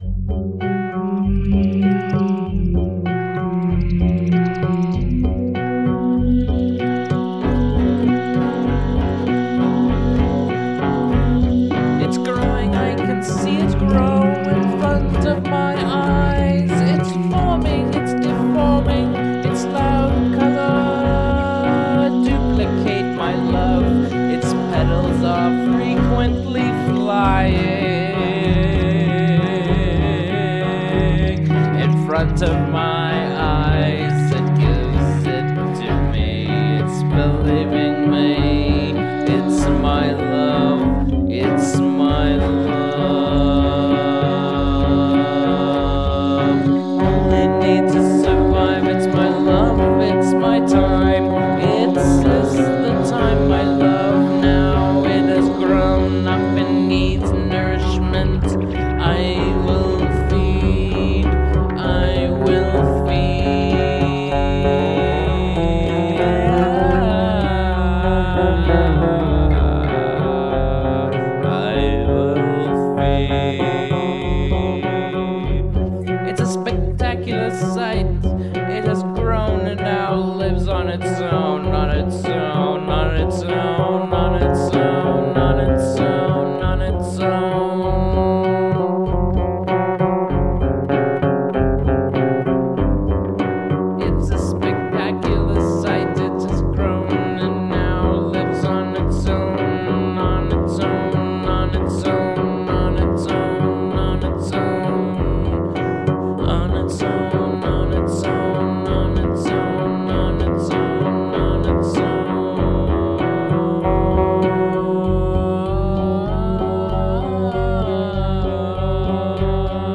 This is a stripped down version